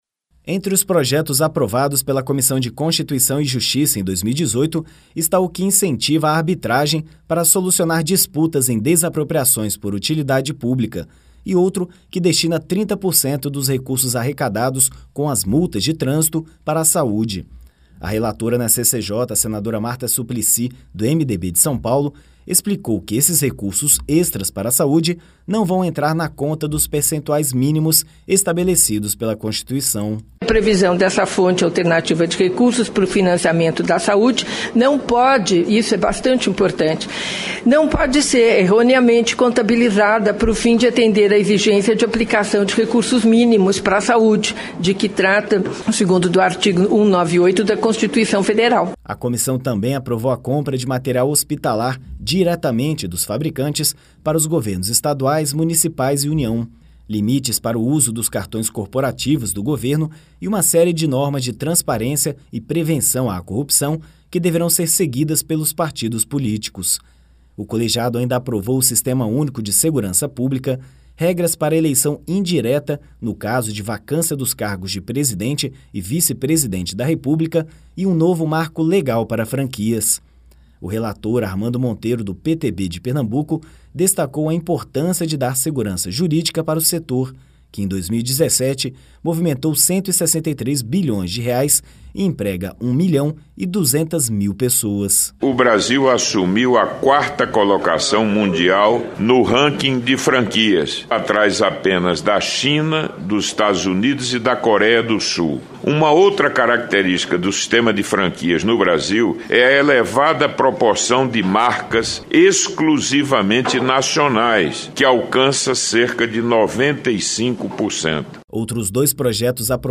A Comissão de Constituição, Justiça e Cidadania (CCJ) aprovou em 2018 a criação de juizados especiais digitais, o fim da exclusividade para delegados de polícia abrirem boletins de ocorrência e multa para quem fuma em veículos com menores de idade. E rejeitou algumas medidas, como a legalização dos jogos de azar e mudanças feitas pela Câmara dos Deputados ao Projeto de Lei das Agências Reguladoras. A reportagem